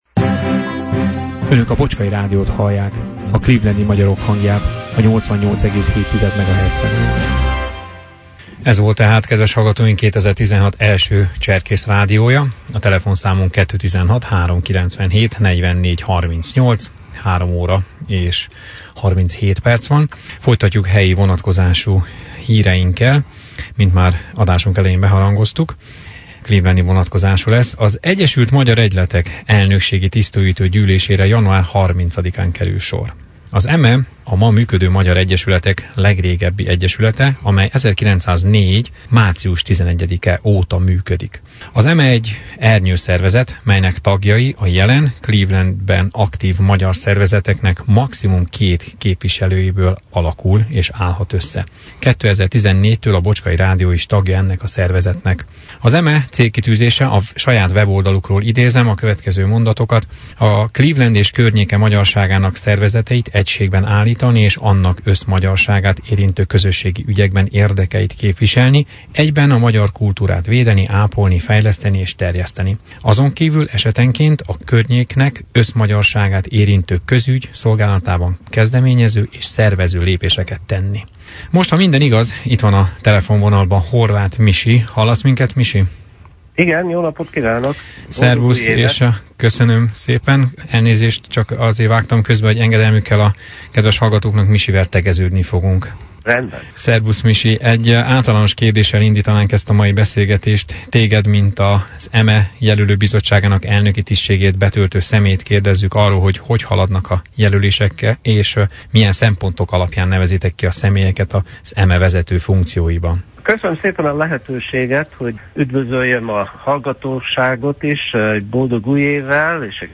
Az Egyesült Magyar Egyletek elnökségi tisztújító gyűlésére január 30-án kerül sor. Ebben a témába kerestük meg és értük el telefonon múlt vasárnapi élő adásunkba